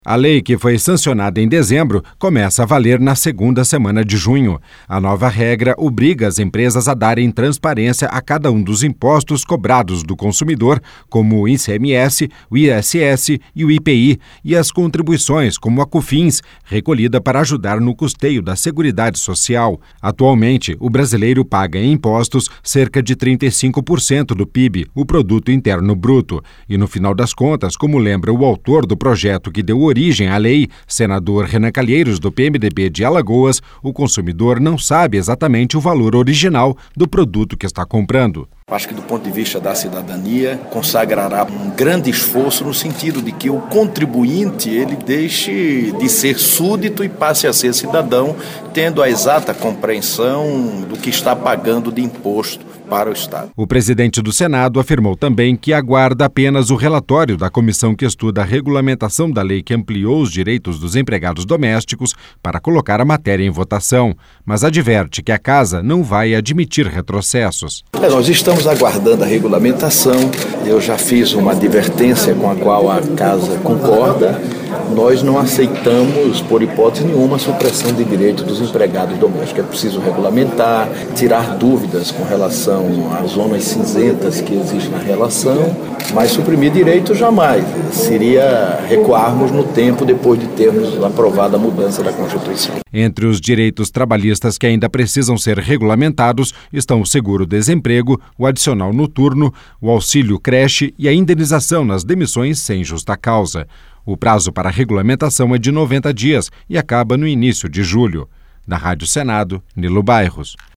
LOC: EM CONVERSA COM JORNALISTAS NESTA SEXTA-FEIRA, RENAN TAMBÉM COMENTOU LEI DE SUA AUTORIA QUE OBRIGA O COMERCIANTE A COLOCAR NA NOTA FISCAL O VALOR DO IMPOSTO PAGO PELO CONSUMIDOR.